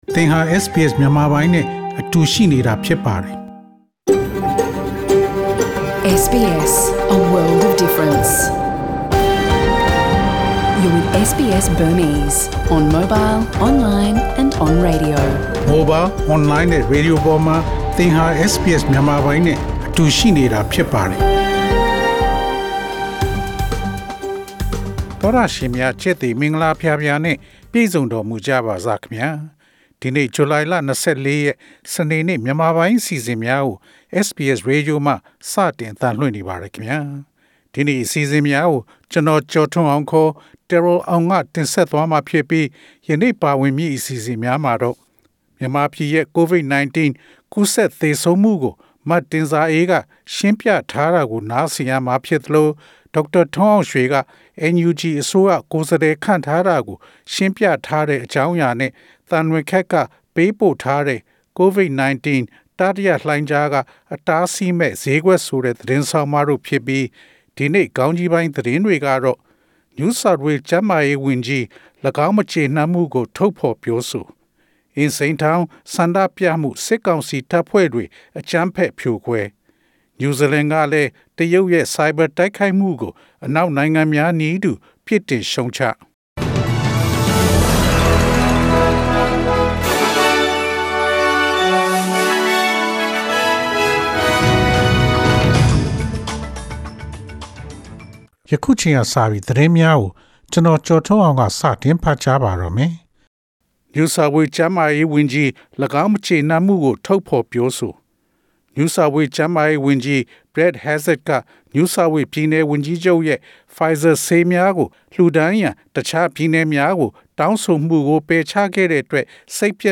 SBS မြန်မာပိုင်း အစီအစဉ် ပေါ့ကတ်စ် သတင်းများ။